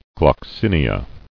[glox·in·i·a]